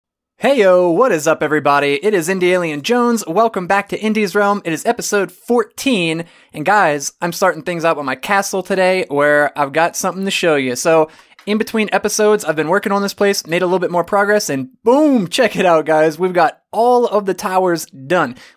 Solved Voice sounds distant in recording
I do voice commentary over gaming videos that I create.
The final result sounds like my voice is slightly distant/thin, for lack of a better description. The chain of effects in Audacity is as follows: Noise Reduction, Compressor, Hard Limiter, EQ, Normalize.
Sounds a bit thin (if you're sucking out all that mid 100's area, maybe don't?).
I done;t hear distant either - I just hear processed.
Your voice is fine, your diction decent (for an American - he he) and the mic itself seems to lack pops and bangs and wind noise.